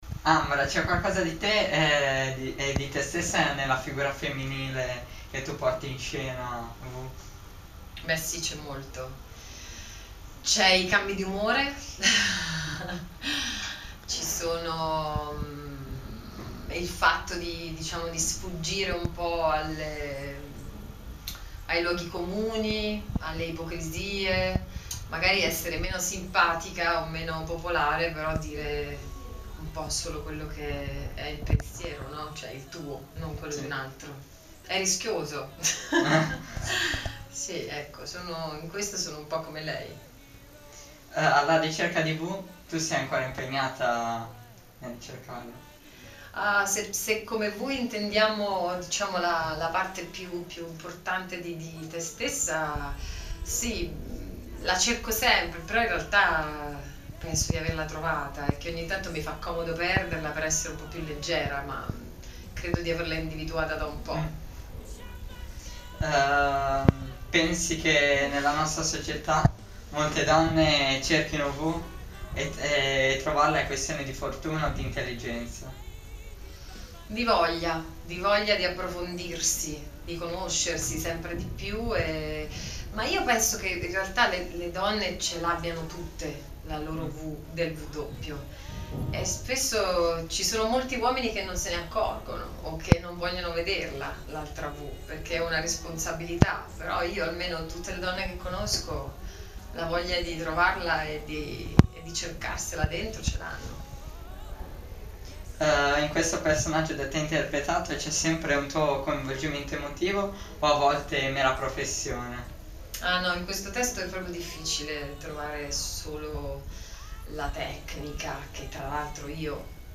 Intervista a ambra angiolini